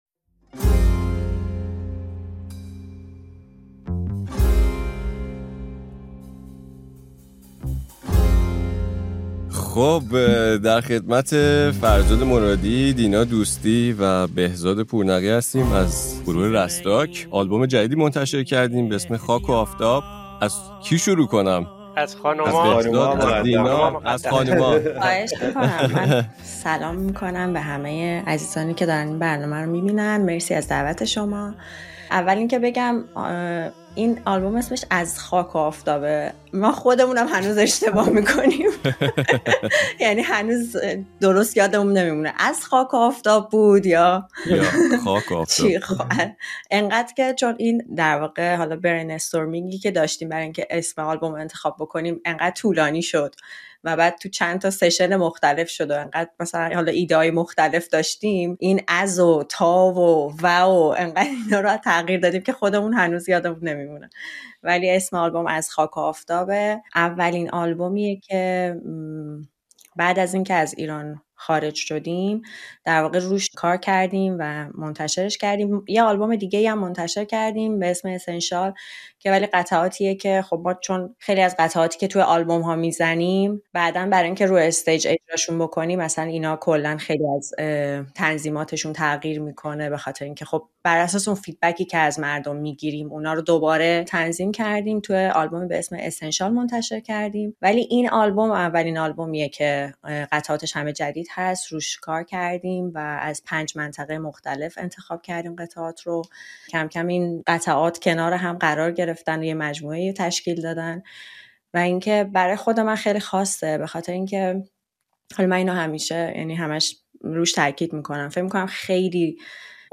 در این مصاحبه ویژه